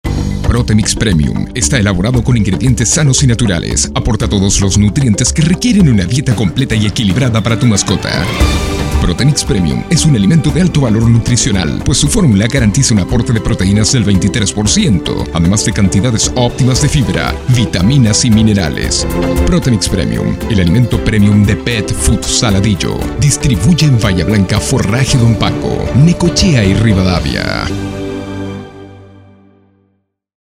spanisch SĂŒdamerika
chilenisch
Sprechprobe: eLearning (Muttersprache):
I have 4 different styles of voice and I can come in neutral tones and as agile and high tones.